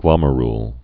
(glŏmə-rl, glŏmyə-)